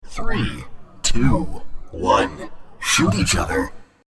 Below is one of the lines with these effects applied.
What I discovered was that the voice sounded a little too much like someone speaking into a toy voice changer.
aud_vo_roundstart02.mp3